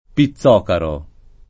[ pi ZZ0 karo ]